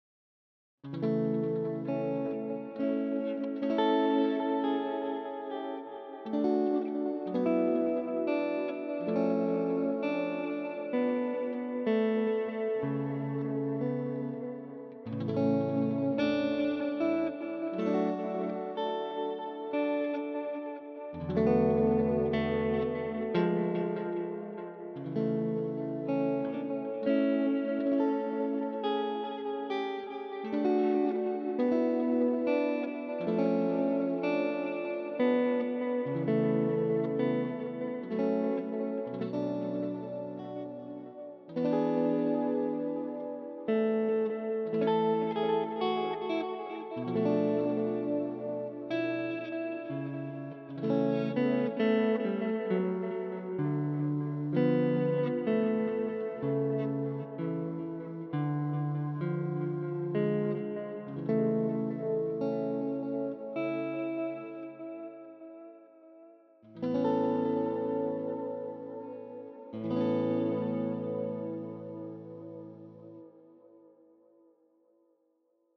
Μου άρεσε πολύ ο high gain Bogner Shiva που έχει, οι νότες είναι σαν να ανυπομονούν να ξεχυθούν απ' τα χέρια σου, αλλά εκεί που όντως ευχαριστήθηκα παίξιμο ήταν τα καθαρά με delay.
Matchless στη μια πλευρά, Boogie στην αλλη.